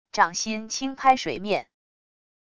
掌心轻拍水面wav音频